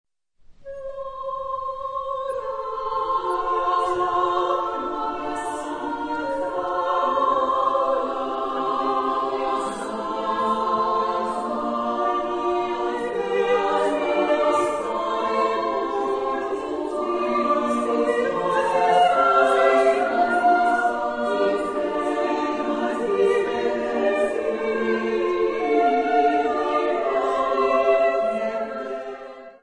Genre-Style-Forme : Madrigal ; Profane
Type de choeur : SSSAA OU TTTBB  (5 voix égales d'hommes OU égales de femmes )
Tonalité : fa majeur
Réf. discographique : Internationaler Kammerchor Wettbewerb Marktoberdorf 2007